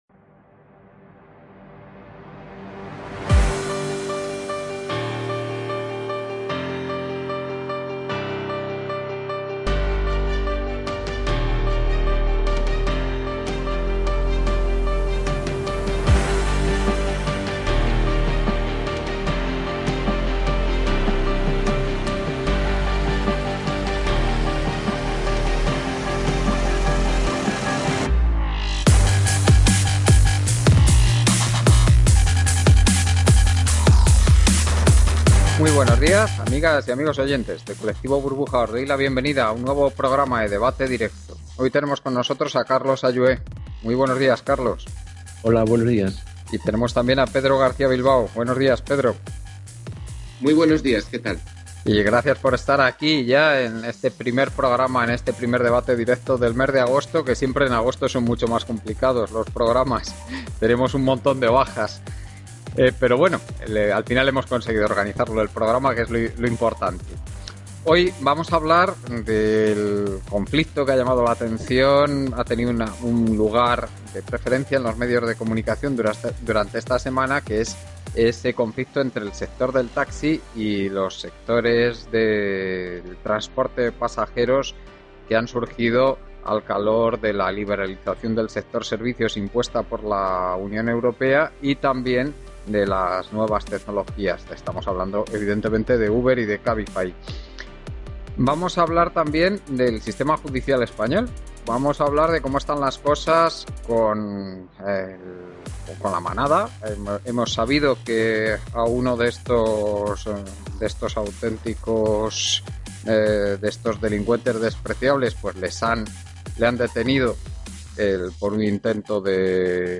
Debates